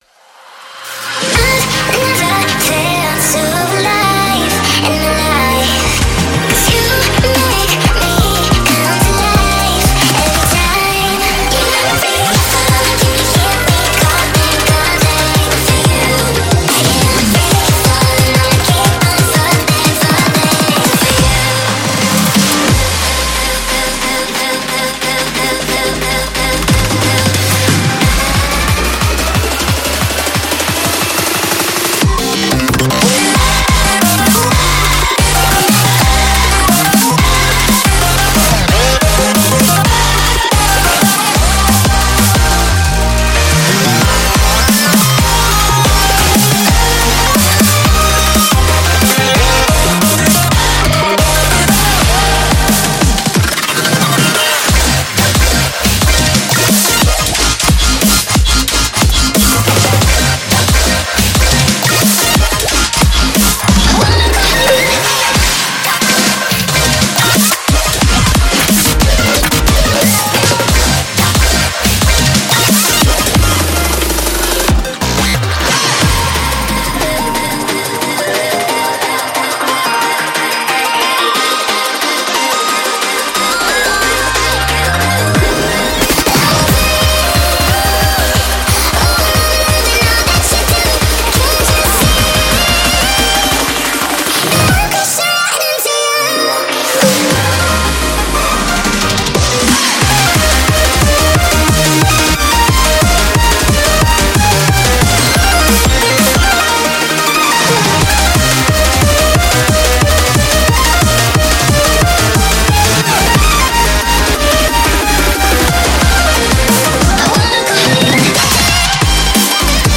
BPM180
Audio QualityPerfect (High Quality)
Comments[J-CORE]